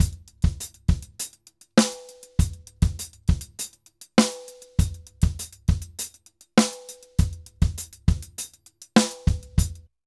Drums_Salsa 100_1.wav